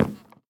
wood1.ogg